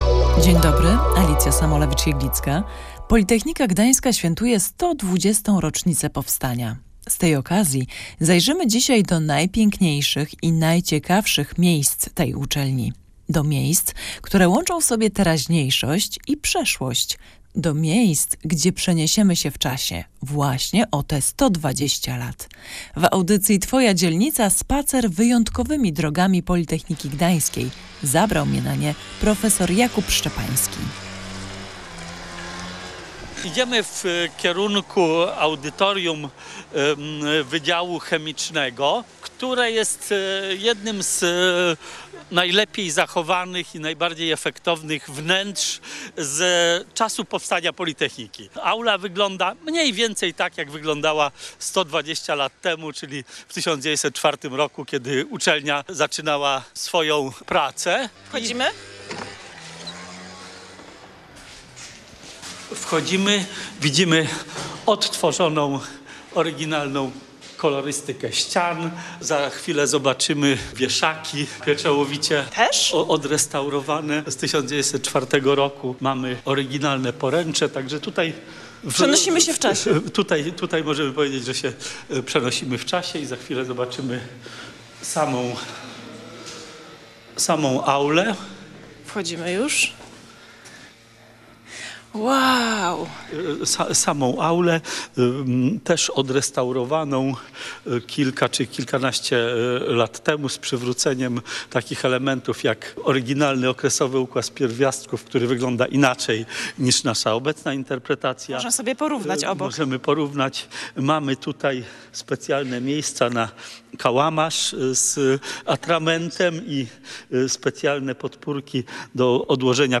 Politechnika Gdańska świętuje 120. rocznicę powstania. Z tej okazji w audycji „Twoja Dzielnica” odwiedziliśmy najpiękniejsze i najciekawsze miejsca tej uczelni.
W audycji „Twoja Dzielnica” zapraszamy na spacer wyjątkowymi drogami Politechniki Gdańskiej.